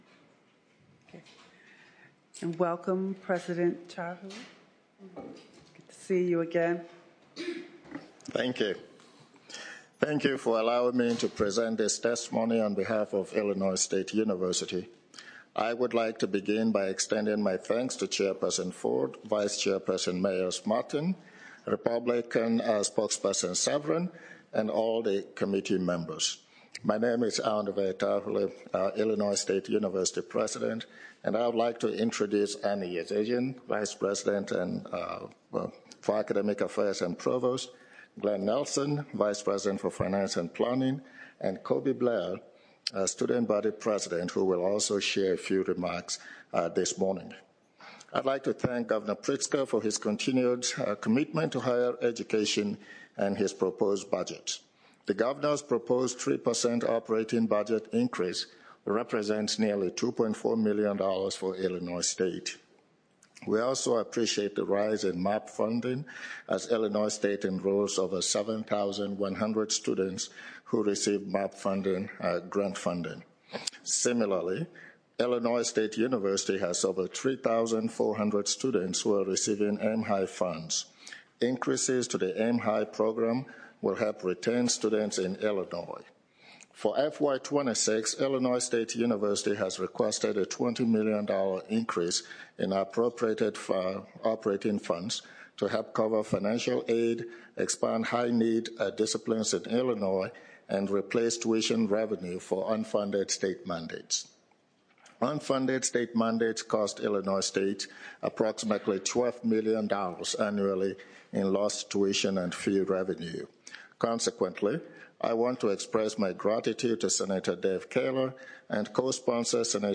Illinois General Assembly Appropriations Testimony volume_up
illinois-state-appropriations-higher-education-4-24-25.mp3